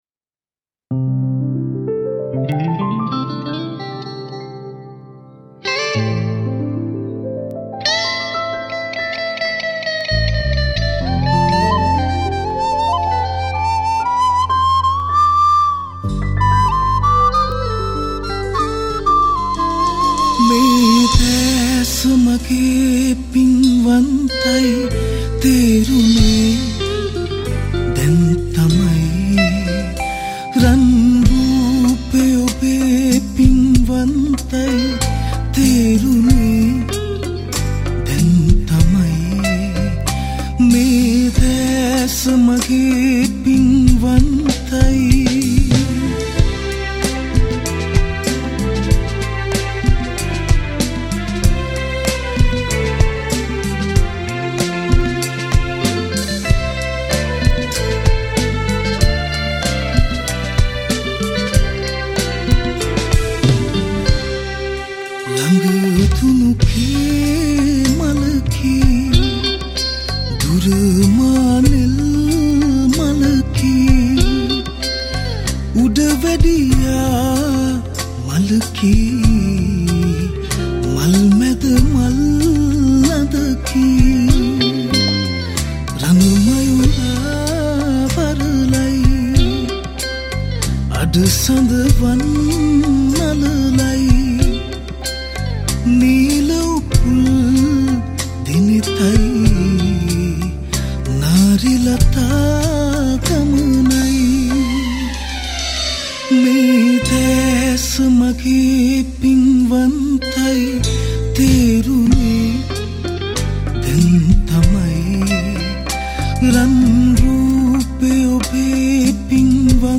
Re arrangement